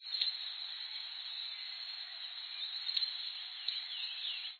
Vogelruf: